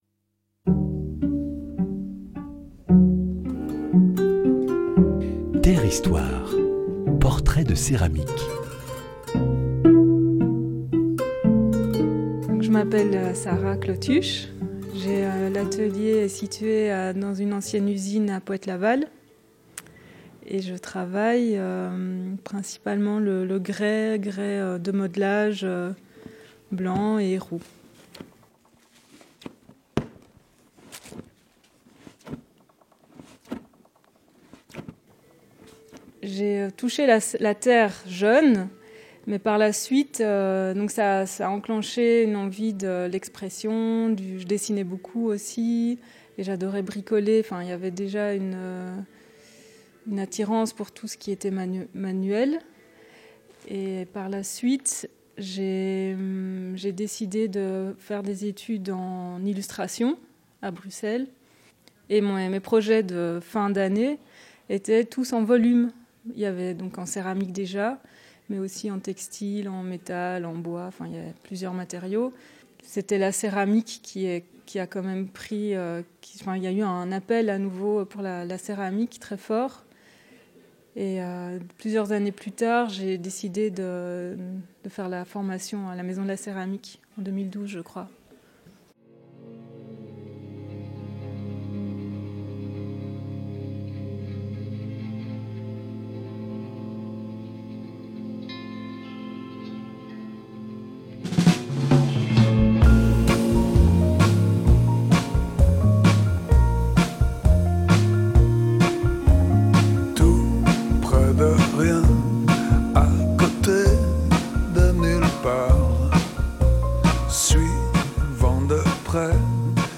Dans le cadre du 14e marché des potiers de Dieulefit, portraits de céramiques en atelier: matériaux, gestes créatifs, lien avec la matière, 8 potiers évoquent la terre de leur quotidien….